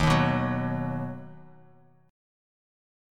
Ebm#5 chord